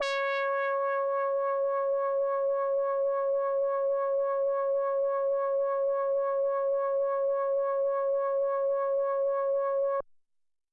Roland JX3 P 摇摆不定的黄铜 " Roland JX3 P 摇摆不定的黄铜 C6 (0AOZM)
标签： CSharp6 MIDI音符-85 罗兰-JX-3P 合成器 单票据 多重采样
声道立体声